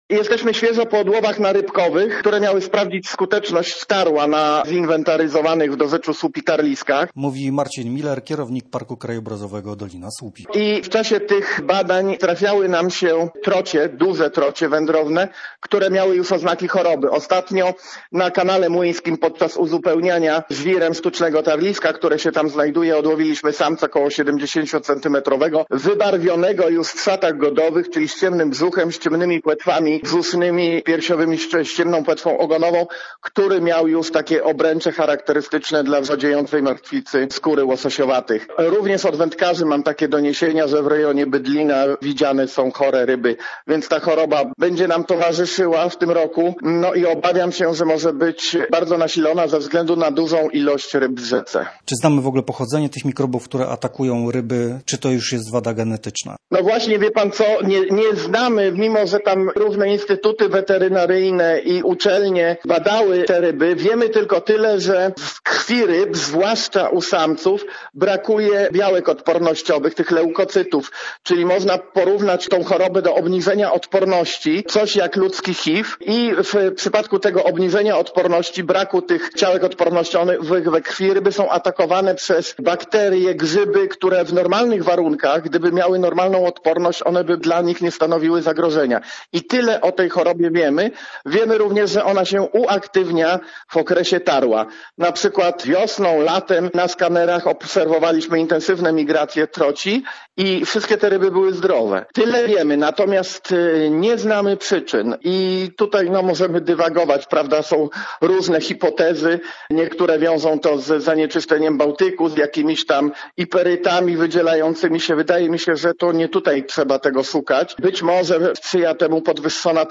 Posłuchaj materiału reportera Radia Gdańsk: